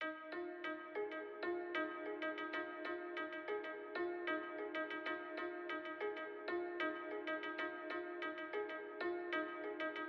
用钟声混响的钢琴
Tag: 95 bpm Weird Loops Piano Loops 1.70 MB wav Key : Unknown